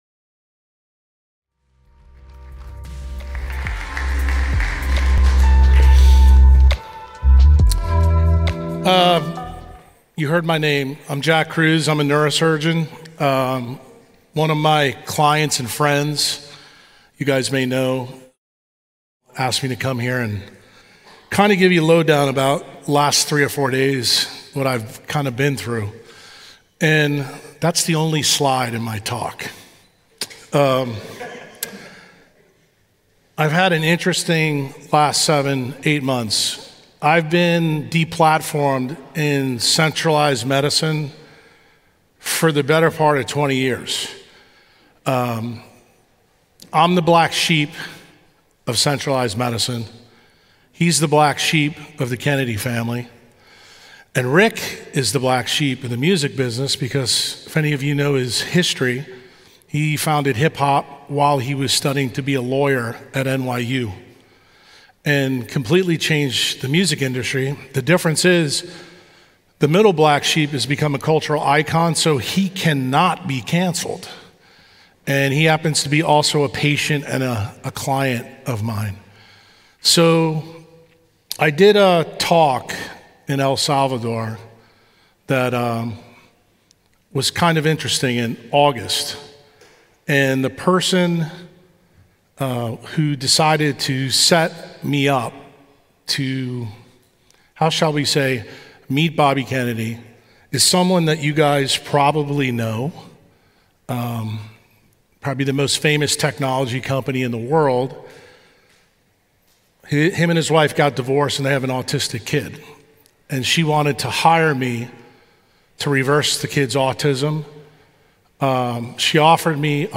Assembly 2023